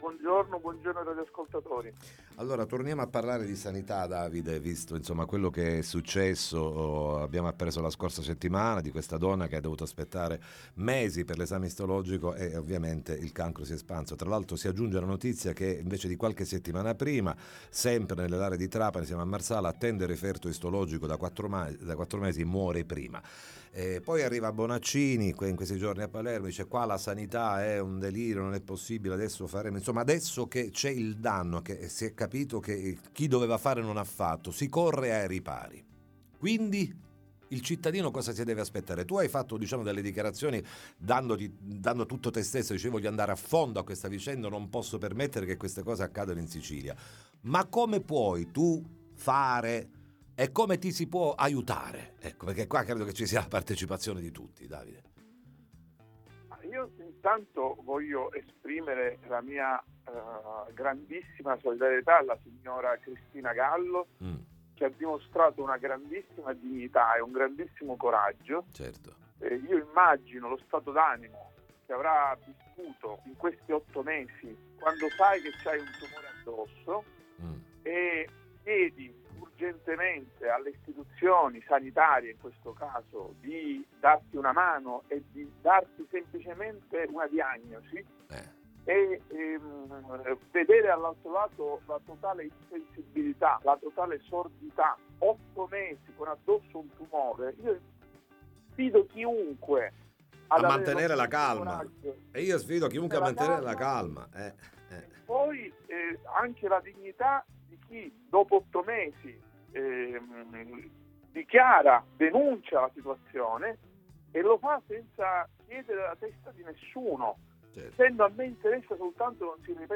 Sanità in Sicilia: la situazione attuale, ne parliamo con Davide Faraone